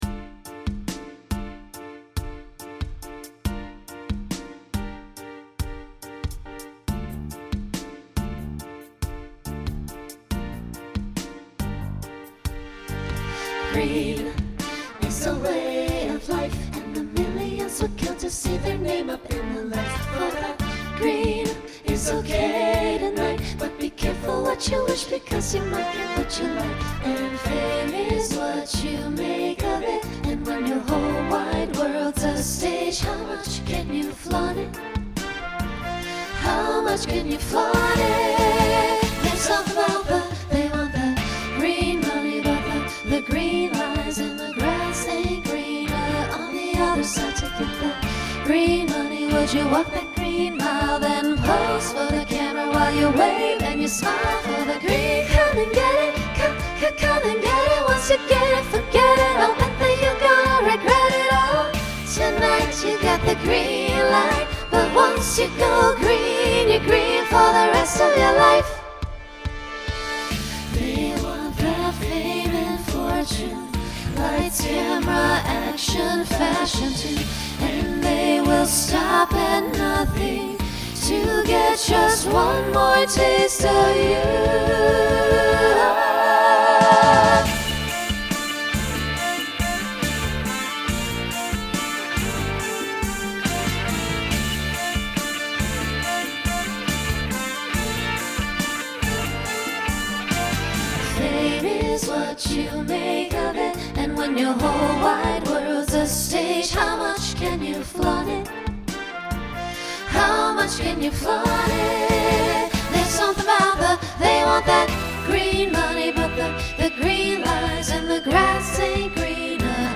Genre Pop/Dance Instrumental combo
Mid-tempo Voicing SATB